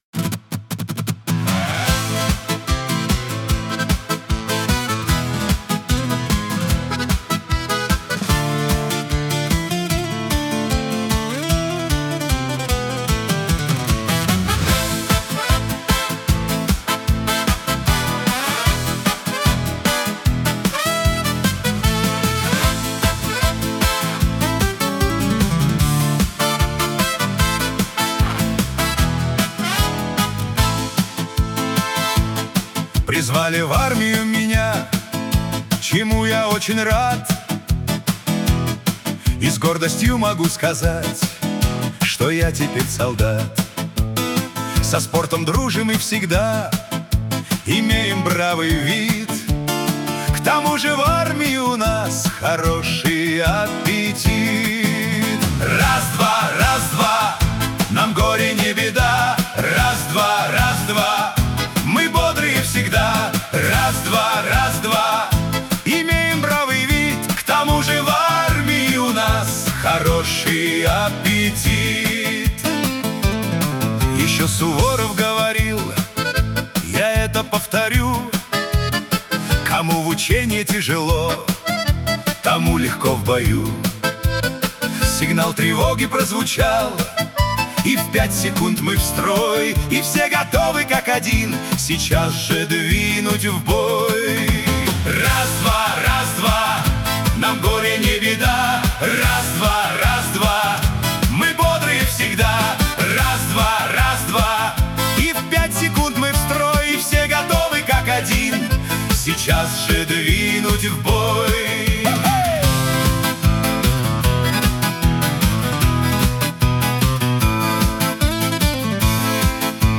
Музыкальный хостинг: /Военная